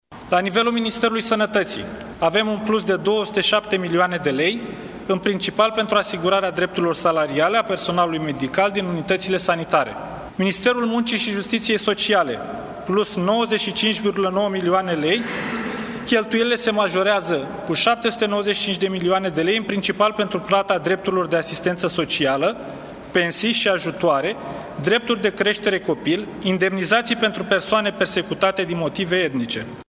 Ministrul de Finanțe, Ionuț Mișa: